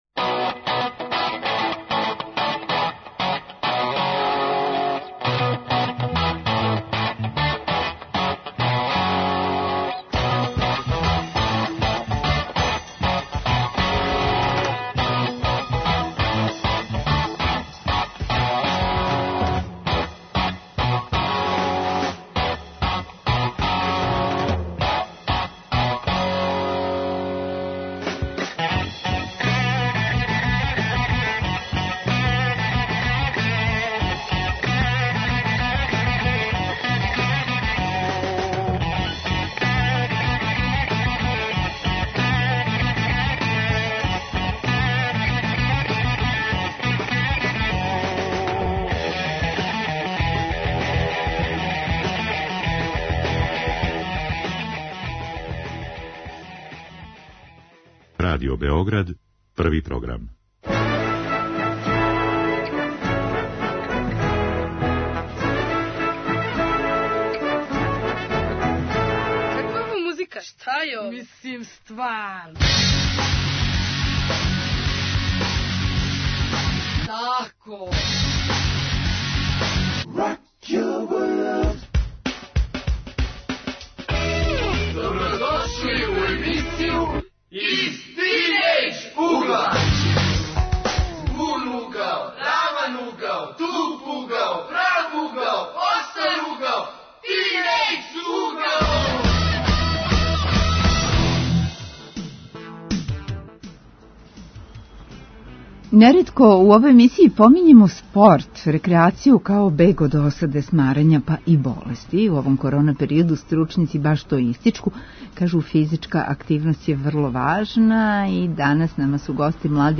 Госту су средњошколци, инструктори овог спорта.